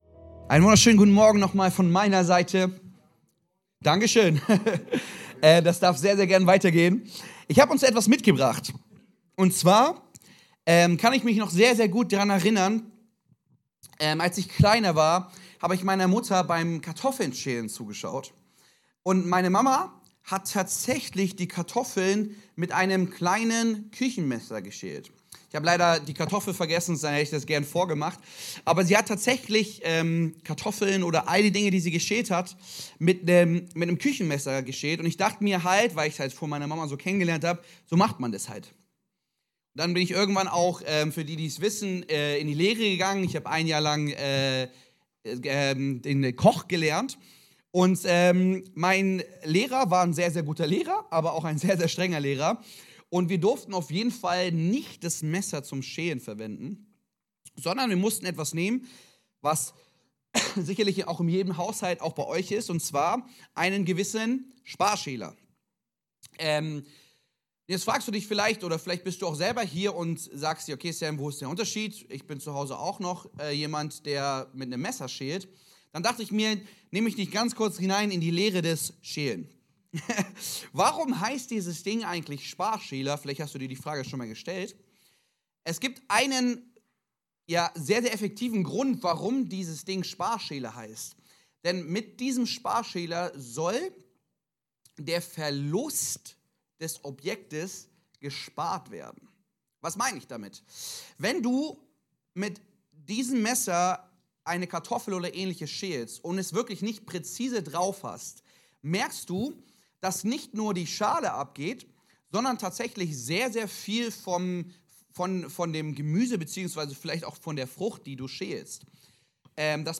In dieser Predigtserie geht es um den Heiligen Geist, der an Pfingsten nach der Auferstehung Jesu, auf die erste Gemeinde kam und seit dem die befähigende Kraft in jedem Gläubigen ist. Mit dem Heiligen Geist wird natürliches Leben ganz natürlich übernatürlich.